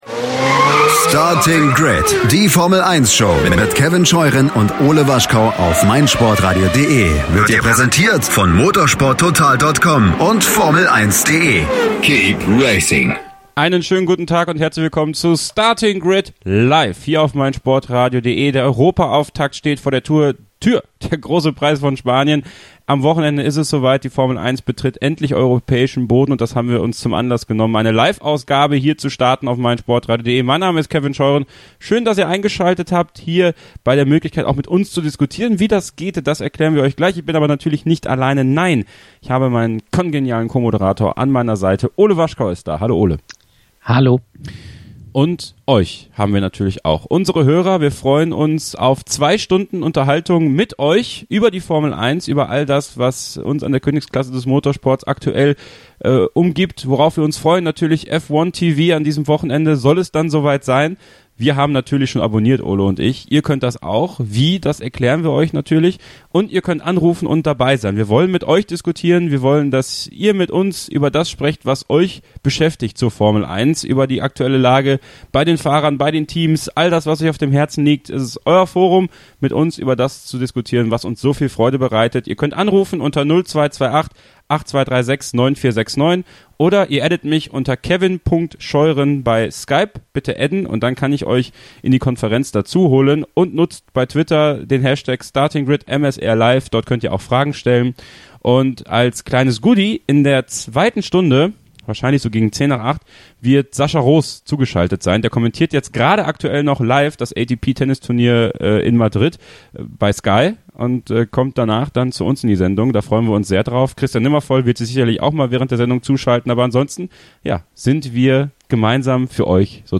Wir wünschen euch viel Vergnügen mit der Wiederholung der Live-Ausgabe vom 09.05.2018 und würden uns freuen, wenn ihr uns auf Facebook folgt und dort auch unserer Gruppe "Starting Grid F1 Fans" beitretet.